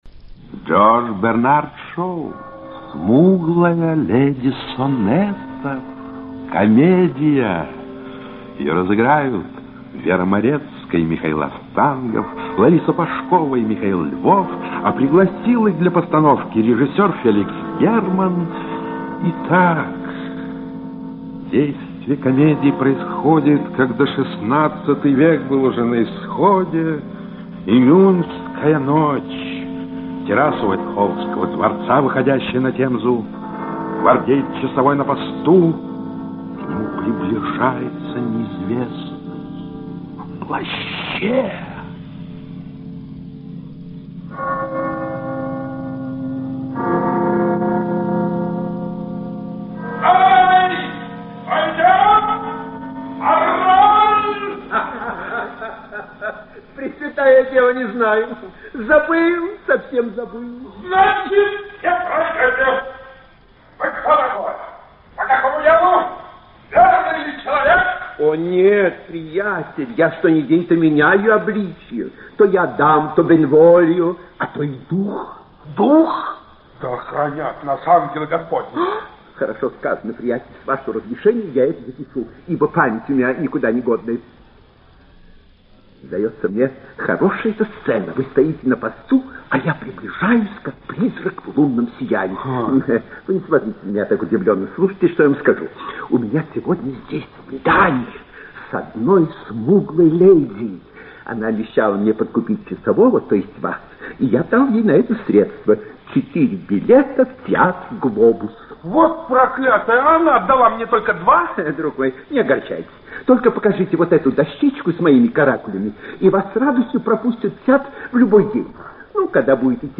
Аудиокнига Смуглая леди сонетов (спектакль) | Библиотека аудиокниг